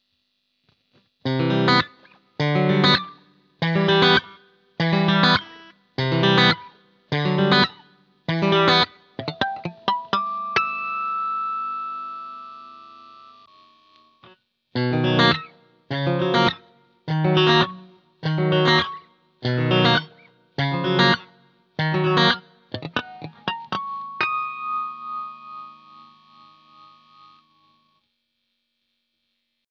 TUNE DOWN
レイテンシー（音の遅延）はというと、残念ながら感じます。
また、トレモロのように周期的に音量が変化するように聞こえます。
エフェクトOFFから弾き始め途中でONにしています。
半音下げ（設定　−１